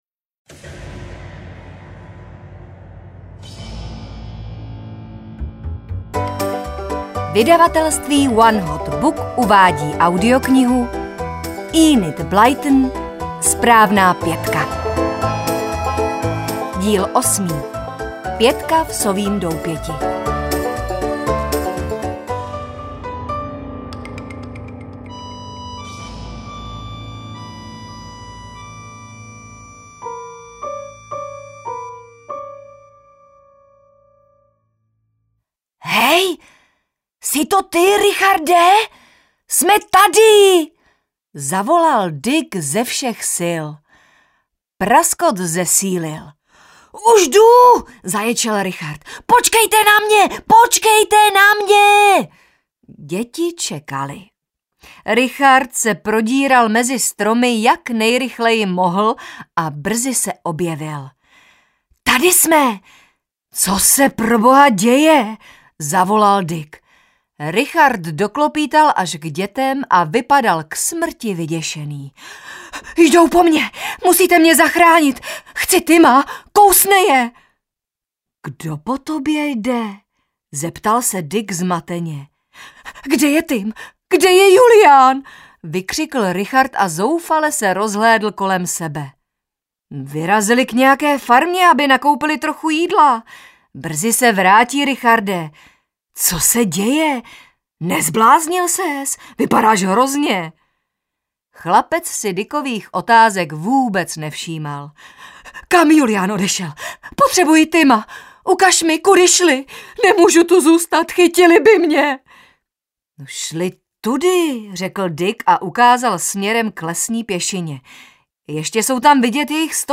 SPRÁVNÁ PĚTKA v Sovím doupěti audiokniha
Ukázka z knihy